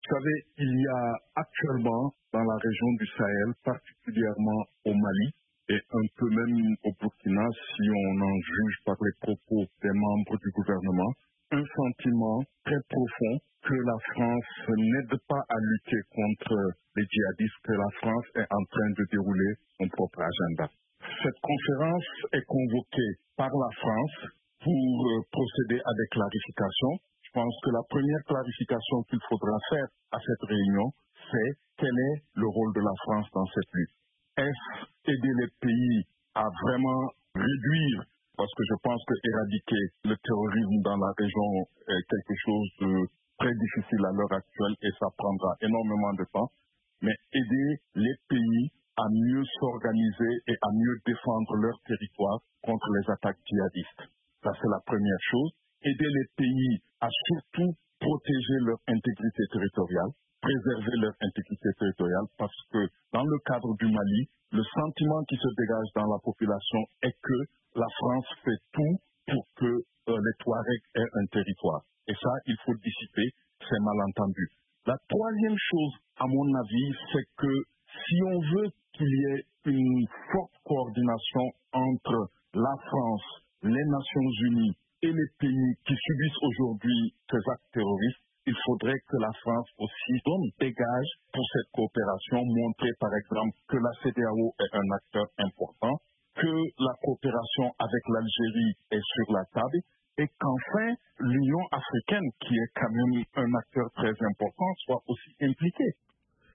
A l’ordre du jour, la dégradation sécuritaire et la propagation djihadiste, ainsi que la présence militaire française dans la région. Interview de l’analyste politique